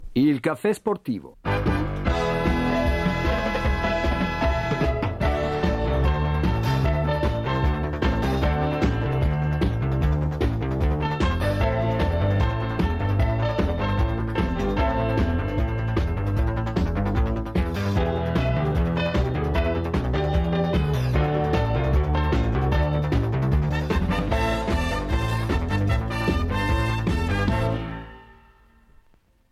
Sigla iniziale